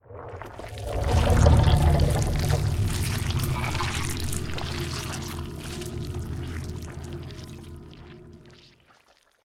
TentacleSpawn.ogg